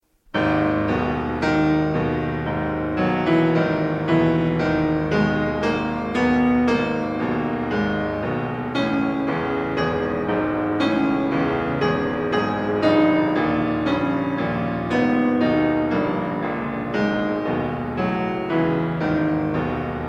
Vertus propres de la musique (temps et mouvement), il va s’avancer vers nous, passer tout près en un impressionnant crescendo, pour s’éloigner lentement et disparaître.